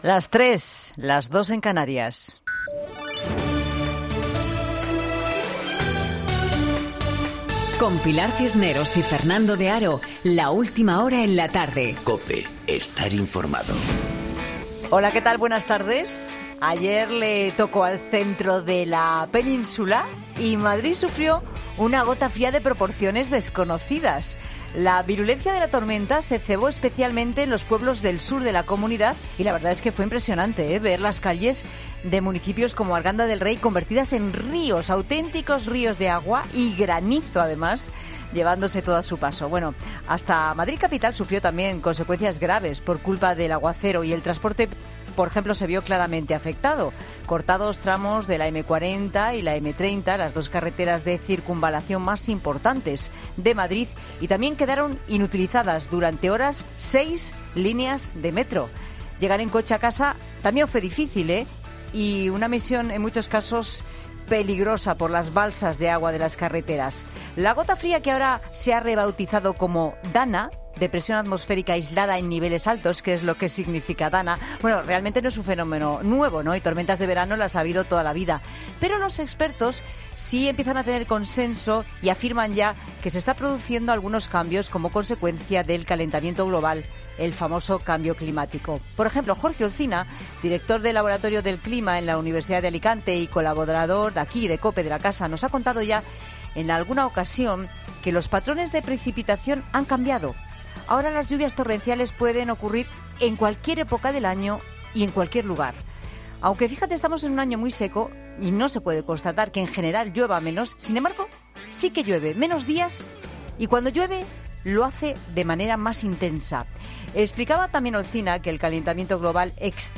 Monólogo de Pilar Cisneros
Pilar Cisneros analiza la actualidad en su monólogo de La Tarde